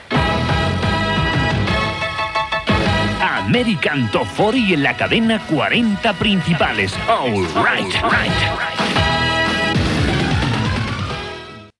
Indicatiu del programa Gènere radiofònic Musical